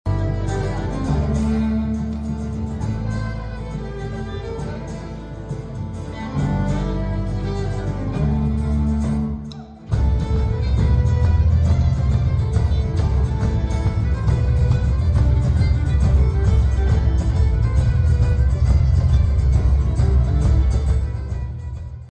a concert at the Emporia Granada Theatre